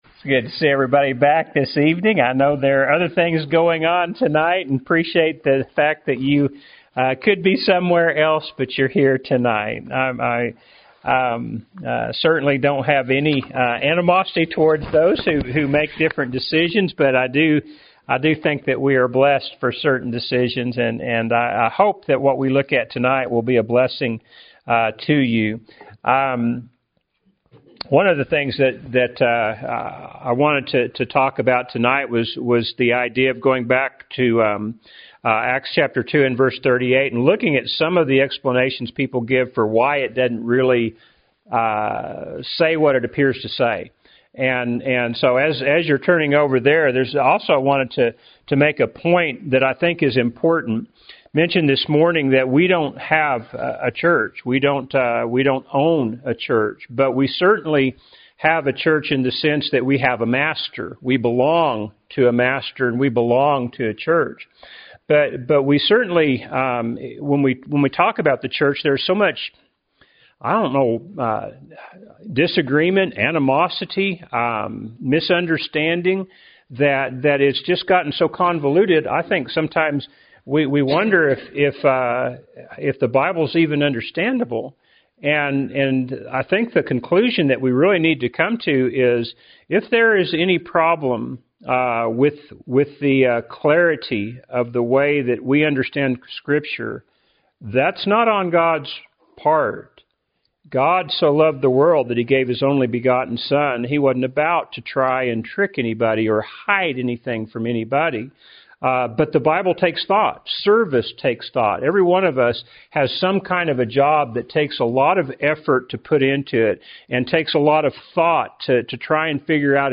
Happy Church of Christ Listen to Sermons